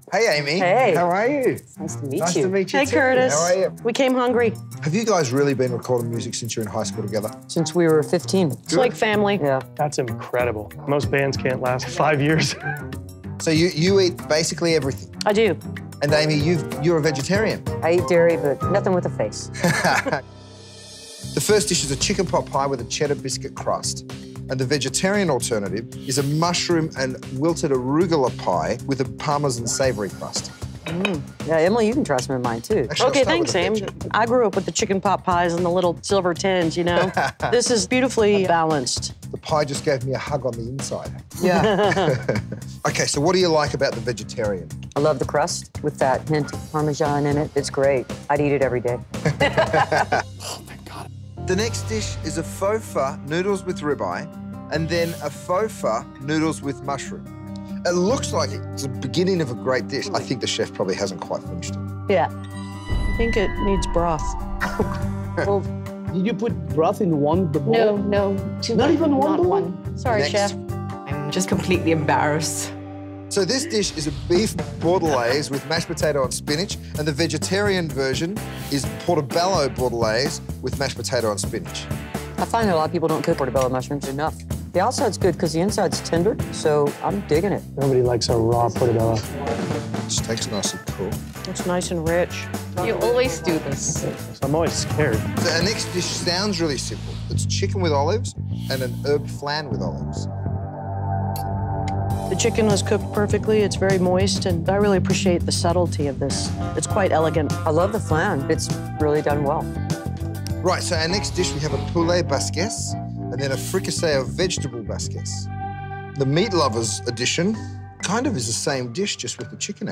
(audio capture of a web broadcast)